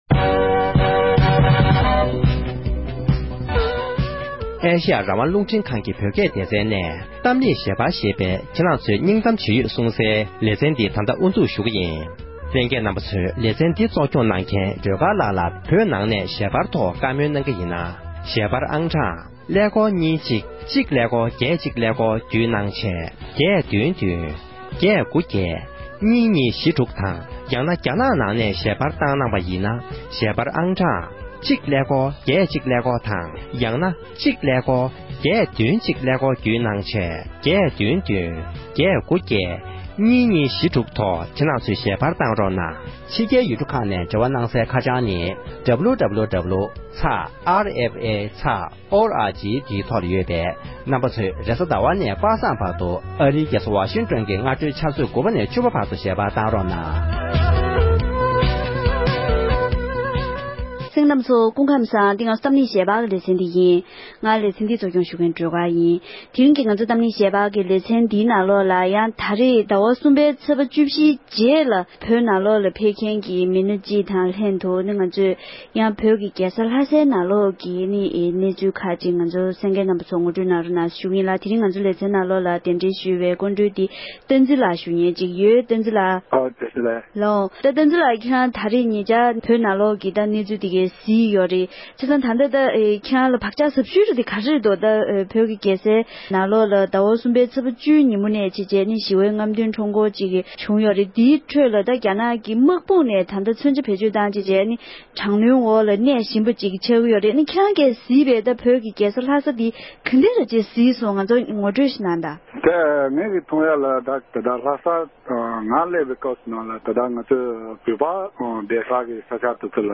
ལྷ་སའི་ནང་ངོ་རྒོལ་དངོས་སུ་གཟིགས་མཁན་དང་གླེང་བ།
༄༅། །དེ་རིང་གི་གཏམ་གླེང་ཞལ་པར་གྱི་ལེ་ཚན་ནང༌།